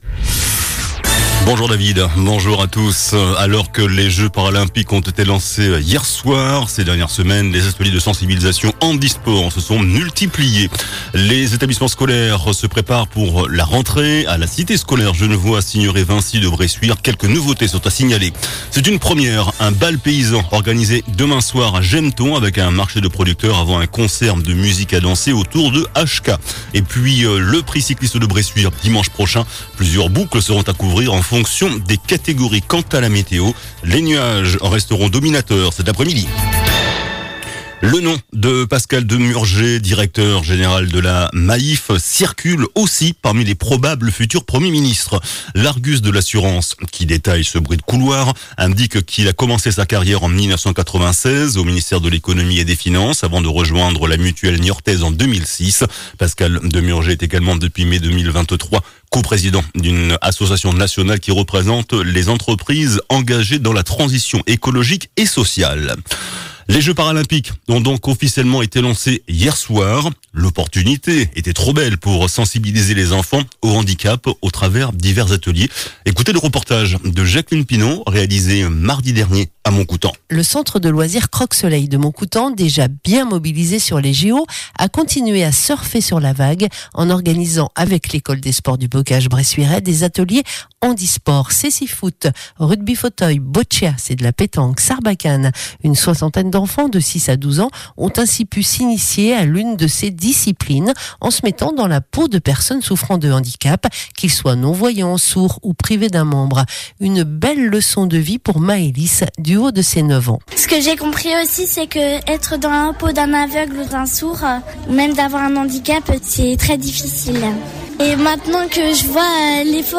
JOURNAL DU JEUDI 29 AOÛT ( MIDI )